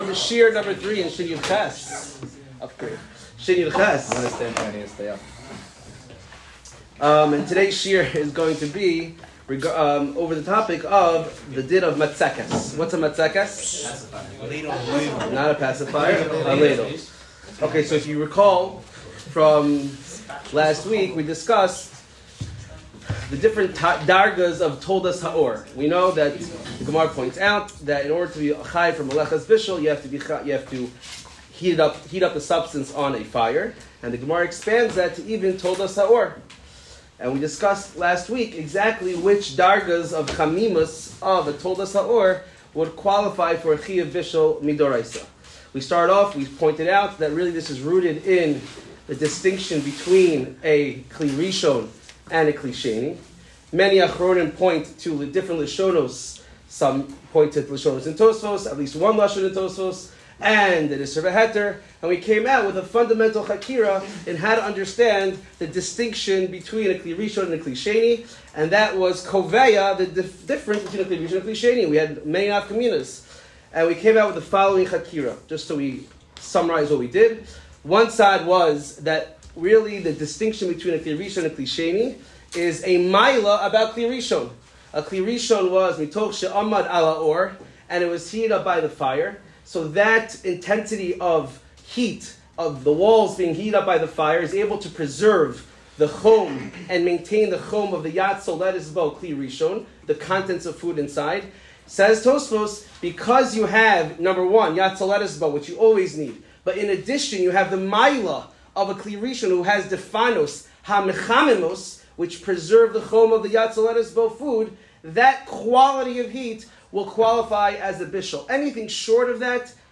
Bishul Shiur 3 - ladles and Kalei bishul